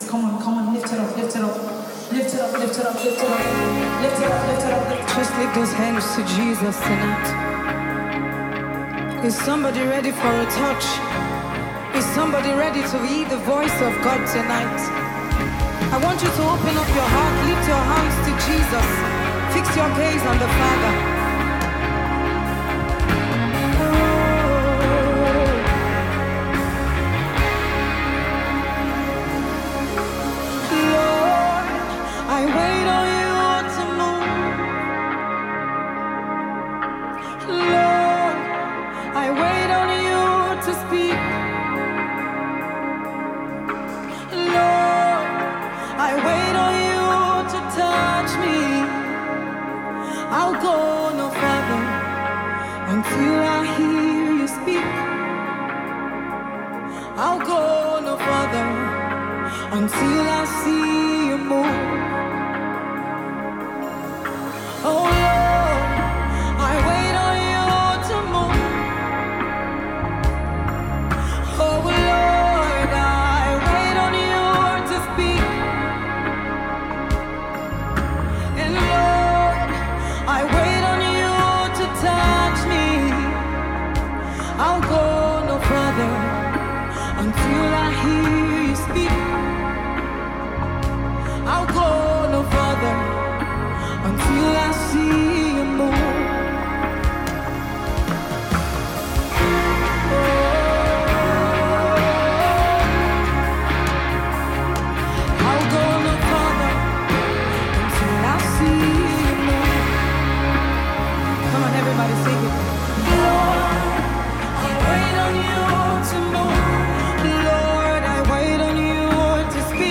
This spirit-filled soft rock worship song
soothing melodies, and heartfelt delivery.
a live recording